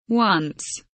once kelimesinin anlamı, resimli anlatımı ve sesli okunuşu